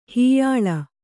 ♪ huyilu